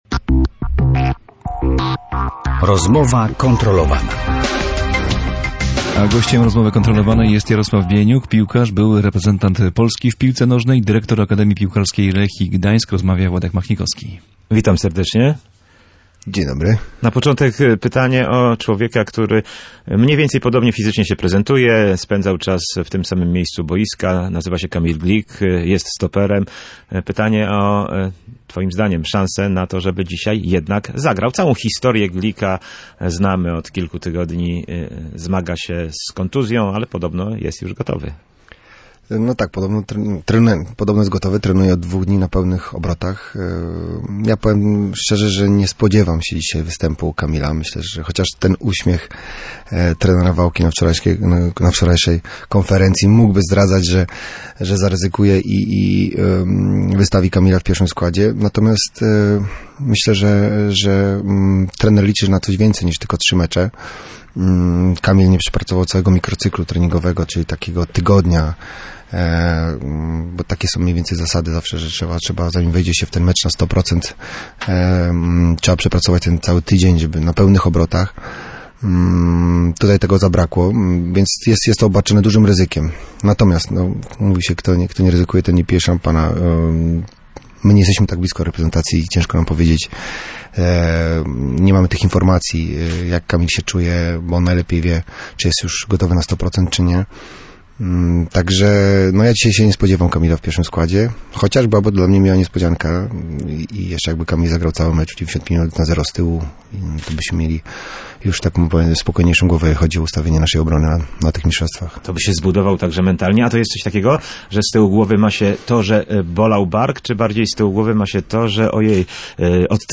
Od czego zależy decyzja co do Kamila Glika? Kto będzie lepszy w środku pola? W jaki sposób szukać gola w meczu z Senegalem? Na te i wiele innych pytań odpowiadał w Radiu Gdańsk Jarosław Bieniuk.